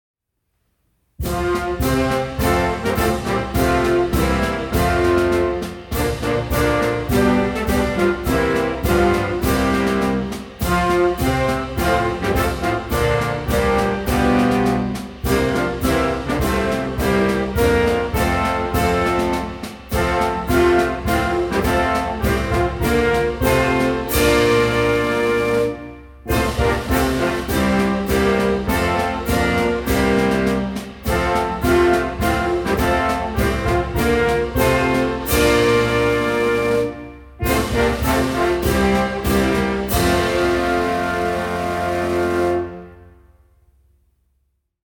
Kiribati_Anthem_Performed_by_US_Navy_Band.mp3